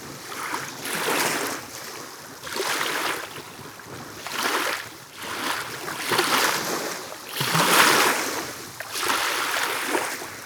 water-still.wav